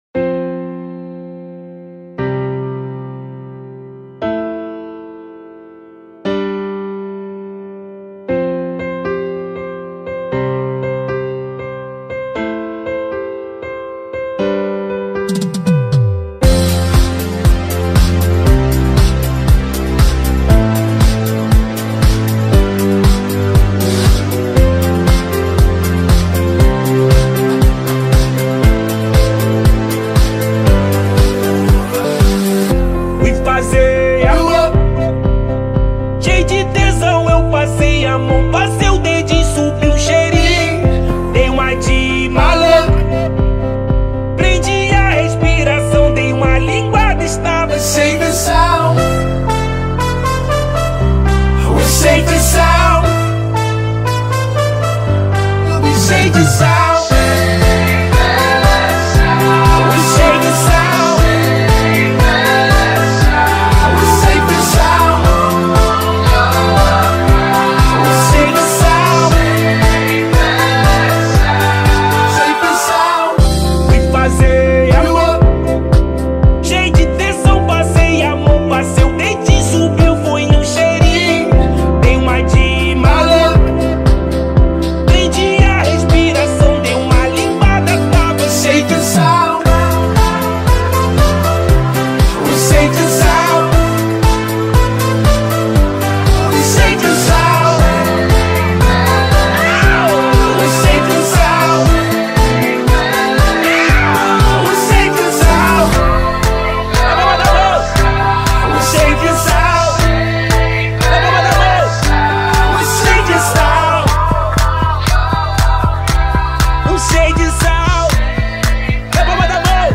2024-10-24 20:58:37 Gênero: Rap Views